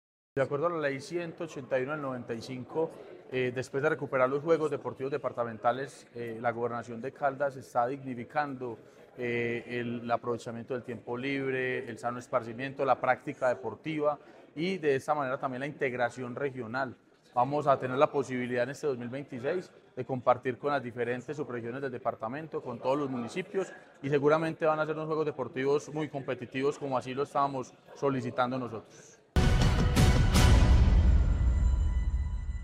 Sebastián Merchán Zuluaga, alcalde de Aranzazu.
Sebastian-Merchan-Zuluaga-Alcalde-de-Aranzazu-SORTEO-JUEGOS-DEPARTAMENTALES-.mp3